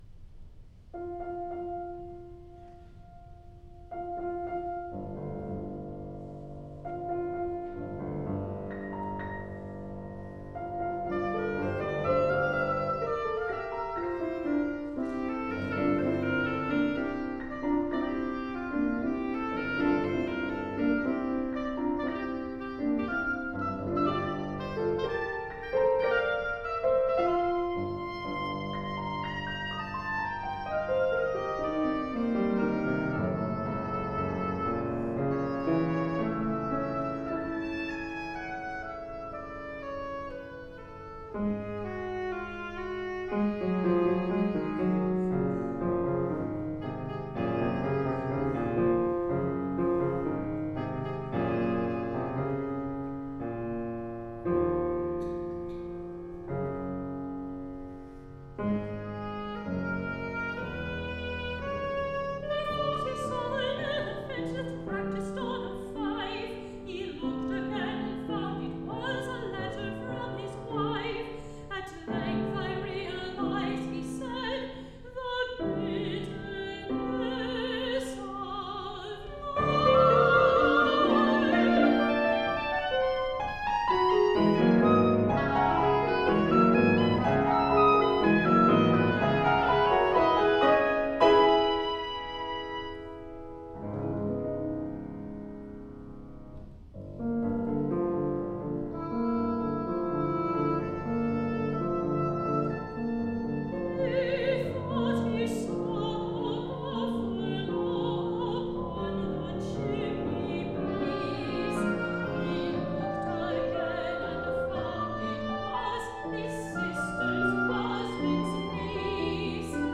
Mezzo-Soprano, Oboe, and Piano
Recording from Feb. 18, 2014 concert
oboe
piano